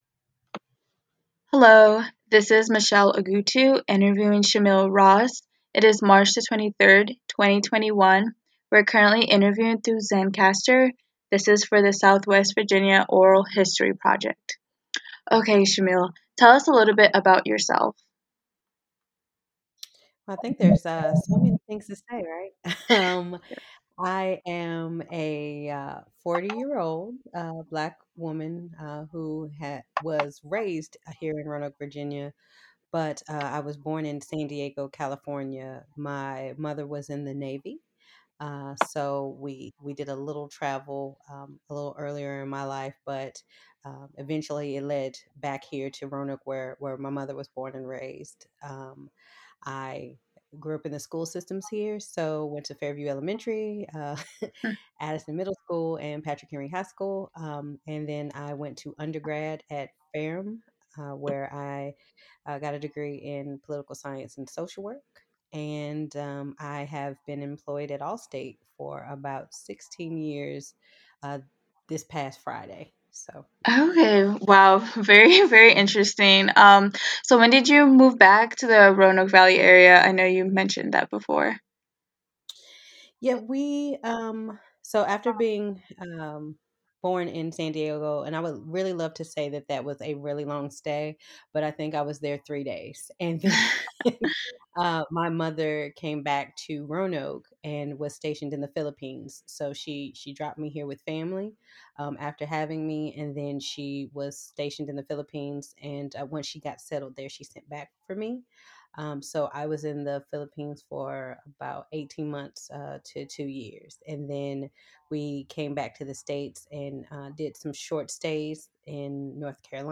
Oral History Interview
Location: Online via Zencastr